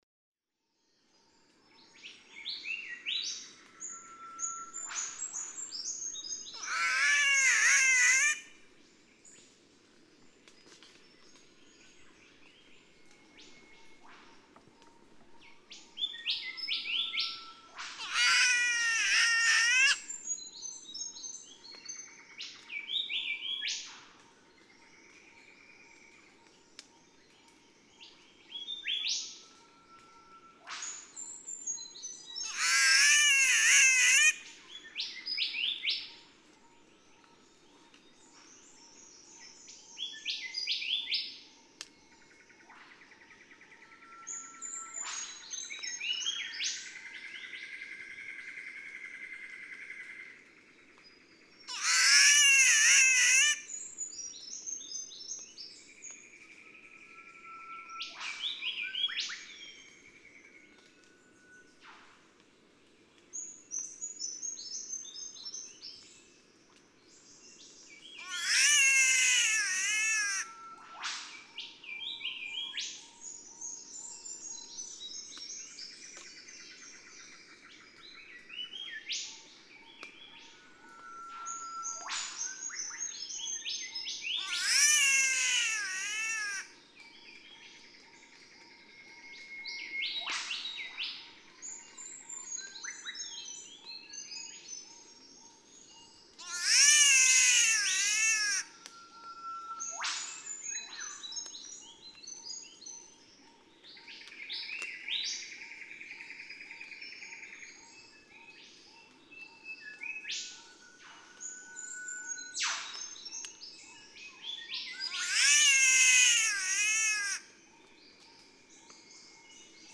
Green catbird
The green catbird, another bowerbird, is no relative of the North American gray catbird, but they're both named for their cat-like meowing, though this green one sounds more like a crying child stuck high up in the tree.
With eastern whipbirds, golden whistlers, and others singing in the background.
O'Reilly's Rainforest Rretreat, Lamington National Park, Queensland.(3:37)
719_Green_Catbird.mp3